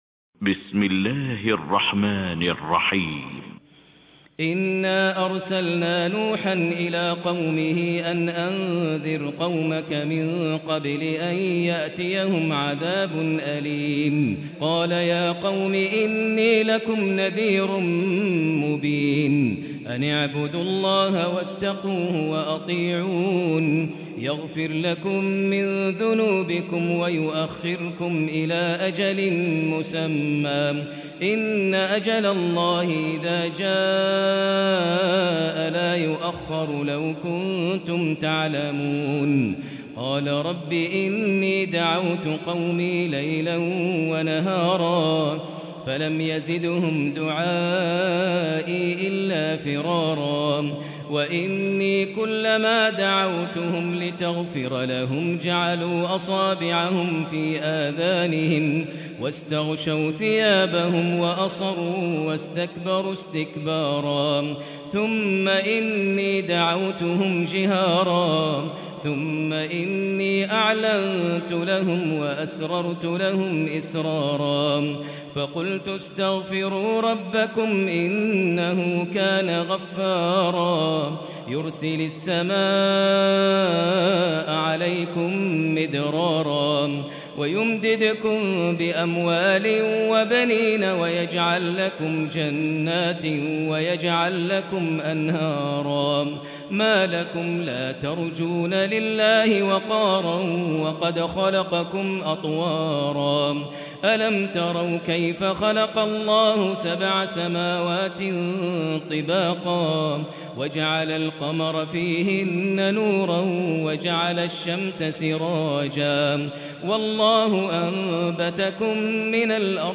Quran recitations
Tarawih prayer from the holy Mosque